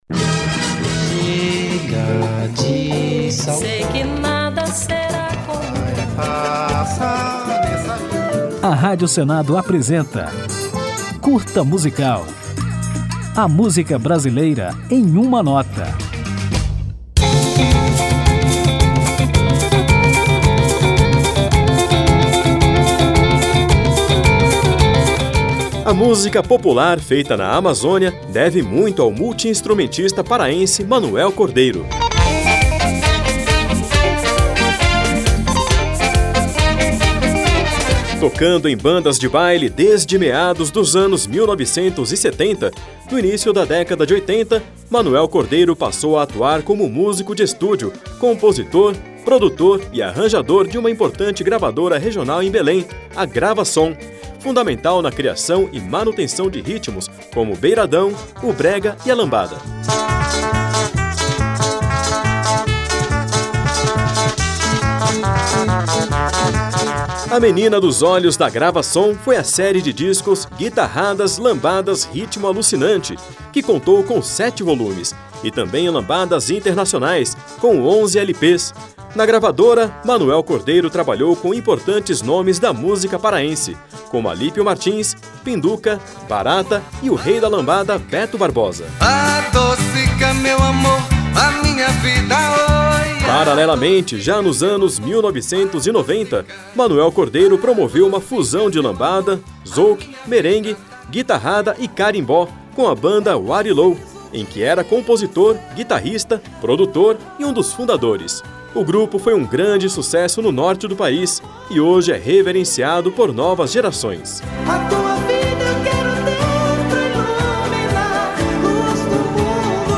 Depois de conhecer a história e reconhecer a imporância de Manoel Cordeiro, vamos ouvi-lo na música Lambada Desumana, lançada em 2019.